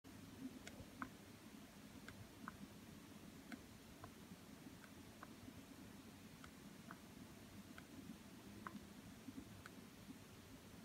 Здесь вы можете слушать и загружать сигналы, сопровождающие измерение уровня кислорода и пульса.
Звук отключения кнопки пульсоксиметра при измерении уровня кислорода и сердечного ритма